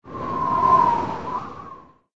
SZ_TB_wind_2.ogg